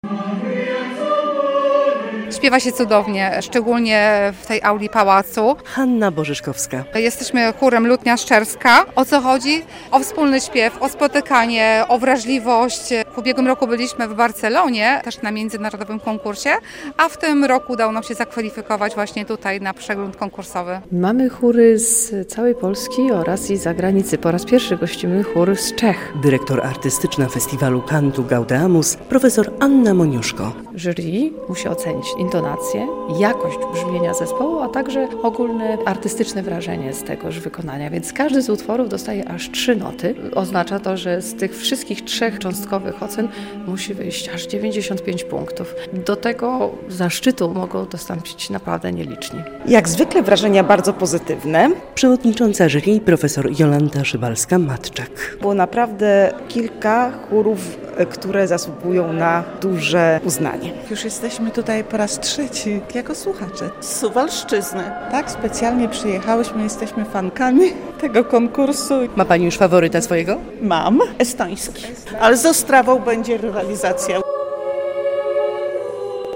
Pałac Branickich rozbrzmiewał w sobotę (31.05) śpiewem chóralnym, a wszystko za sprawą 9. edycji Międzynarodowego Konkursu Chóralnego "Cantu Gaudeamus".
relacja